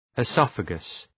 Προφορά
{ı’sɒfəgəs}